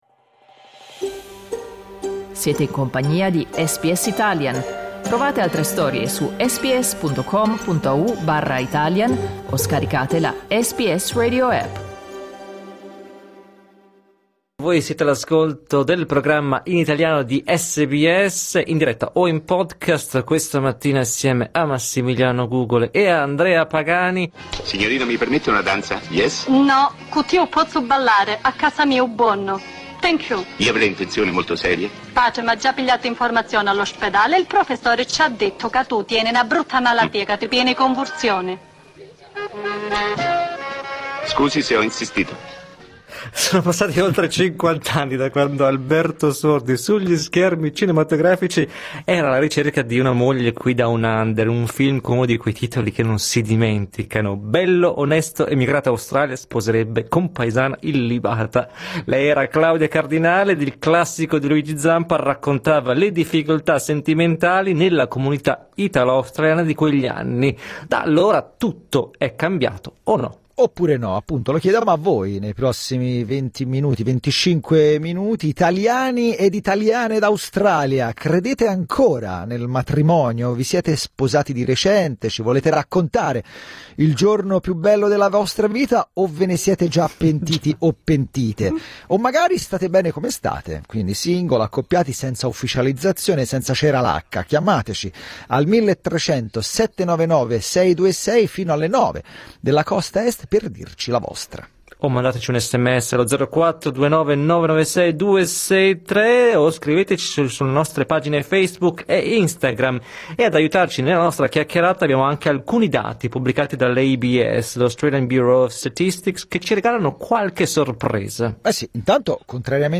Ascolta il podcast con il nostro dibattito in diretta cliccando sul tasto "play" in alto